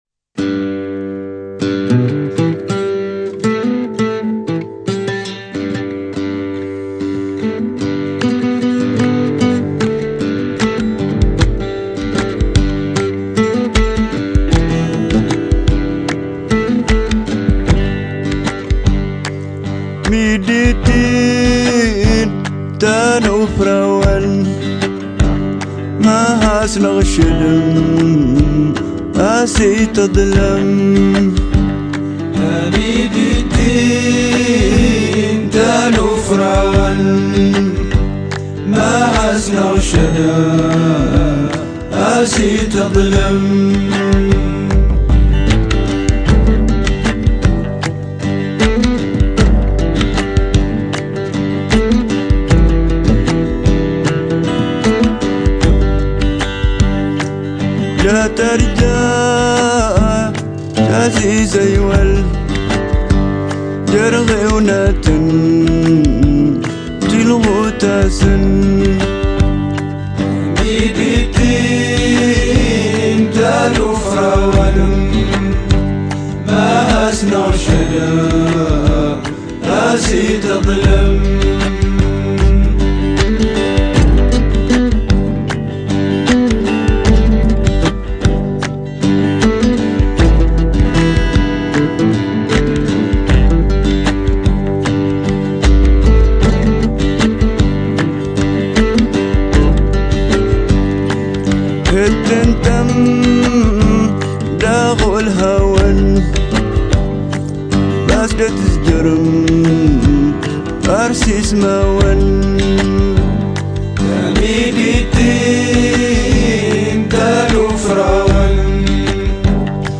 Une musique